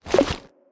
pick_up_2.wav